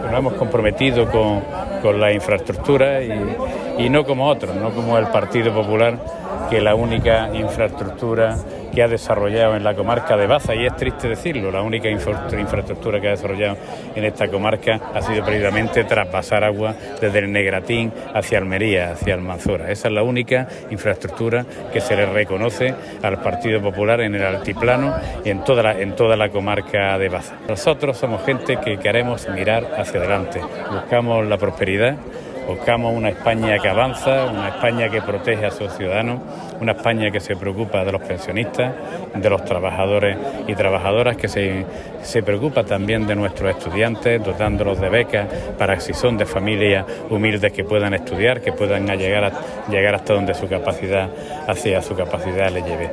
Expresiones que forman parte del mitin ofrecido anoche en la Plaza del Ángel bastetana, al que hace referencia la comunicación que nos han enviado desde el propio PSOE y que junto a las notas de audio y la imagen que la acompañan, reproducimos a continuación:
En un acto comarcal celebrado en Baza junto a la candidata número 1 al Congreso de los Diputados, Carmen Calvo; Entrena ha reafirmado el compromiso del PSOE con todas esas oportunidades.